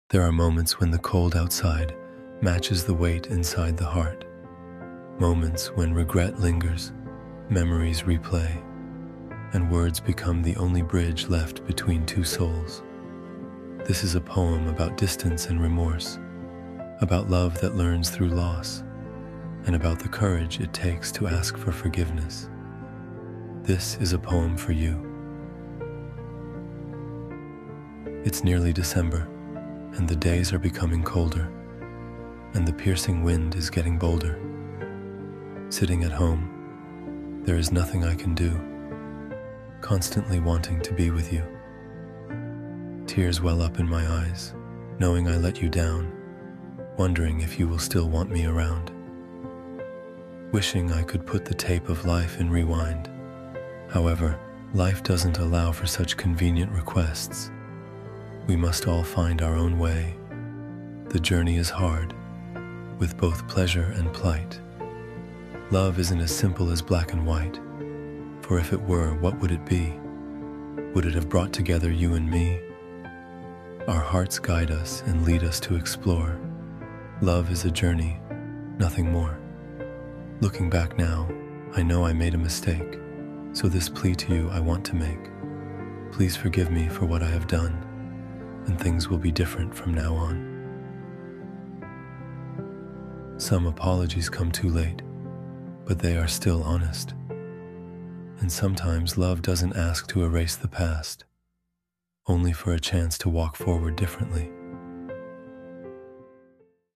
A Poem for You — a romantic spoken word poem about regret, forgiveness, and love that hopes for another chance.
a-poem-for-you-romantic-spoken-word-apology-poem.mp3